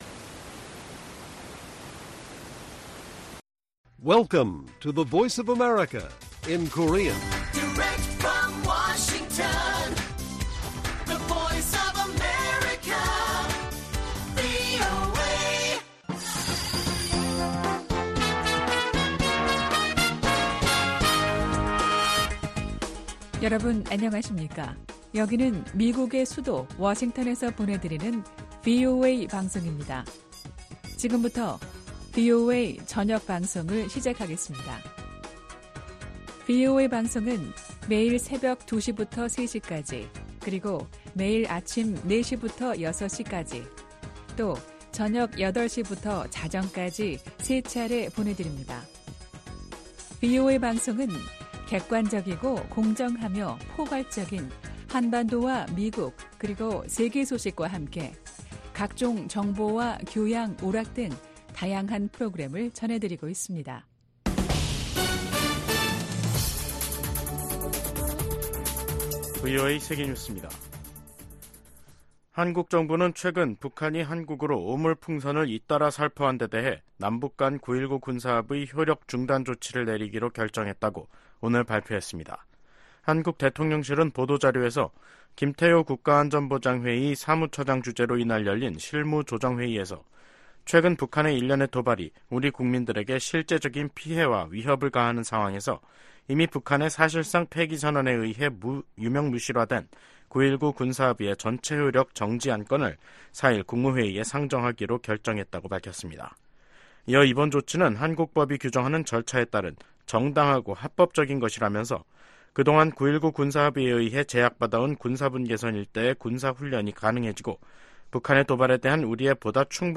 VOA 한국어 간판 뉴스 프로그램 '뉴스 투데이', 2024년 6월 3일 1부 방송입니다. 한국 정부는 9.19 군사합의 전체 효력을 정지하는 수순에 들어갔습니다.